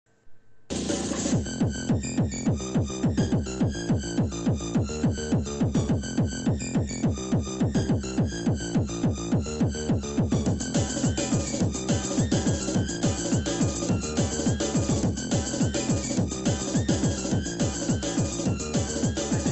cat-rave.mp3